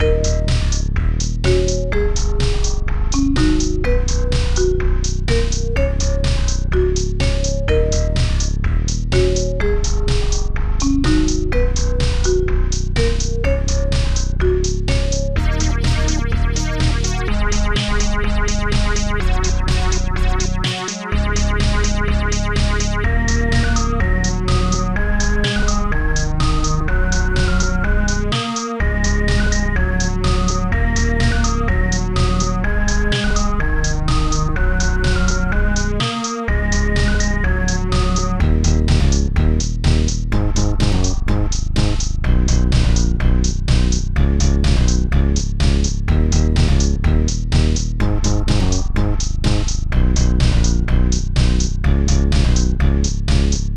2 channels
Instruments popbass faerytale dangerous strings7 slapbass marimba celeste hihat2 popsnare2 bassdrum2